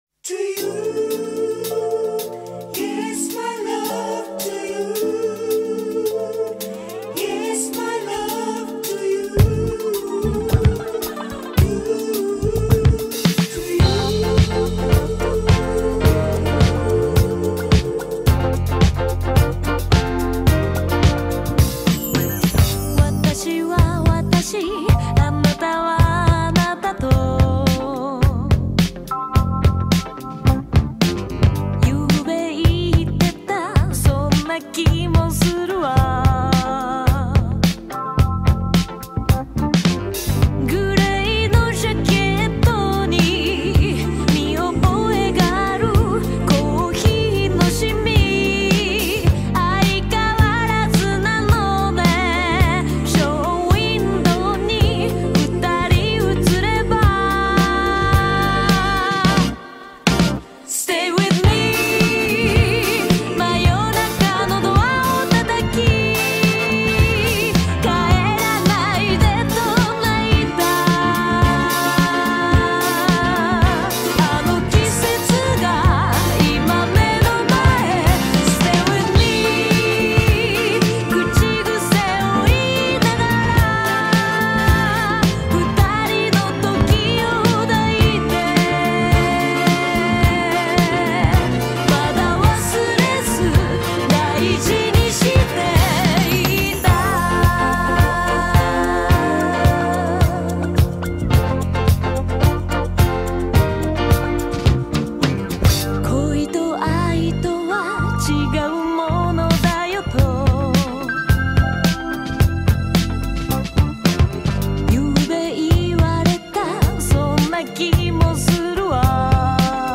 اهنگ قدیمی و نوستالژیک
[Saxophone Solo]